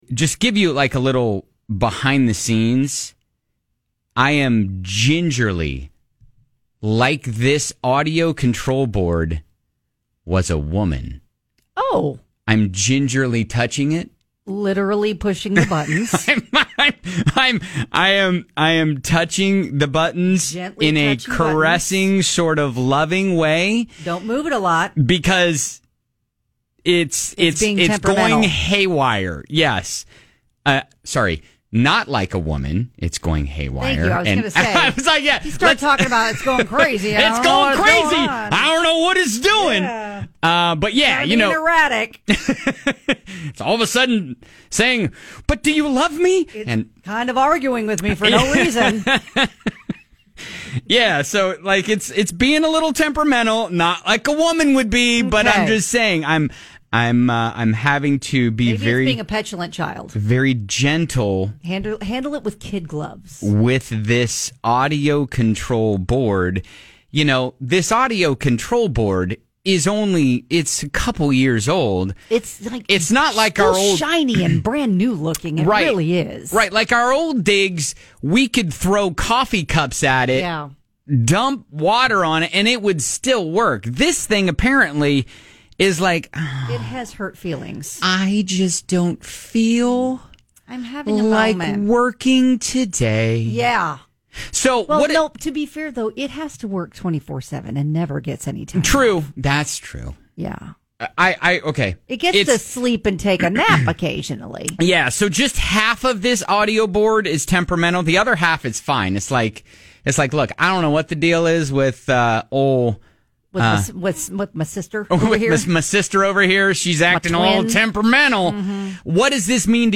Sometimes the audio board gets feisty!